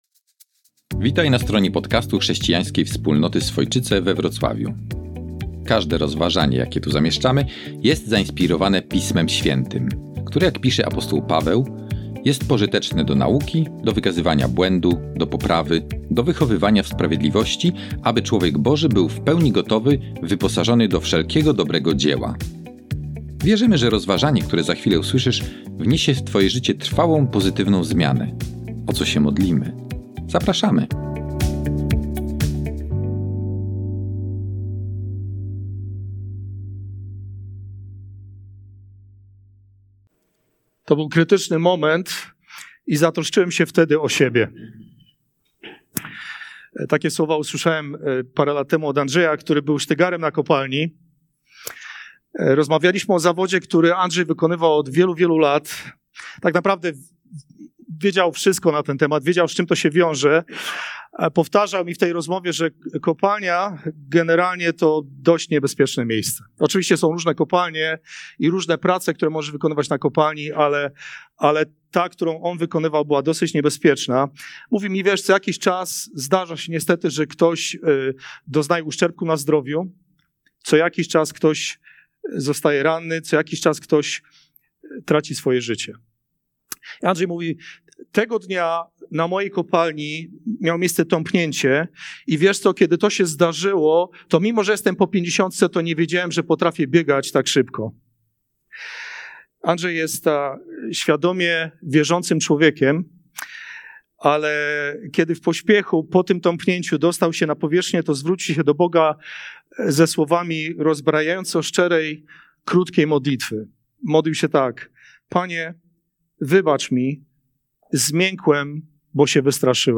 Nauczanie z dnia 29 grudnia 2024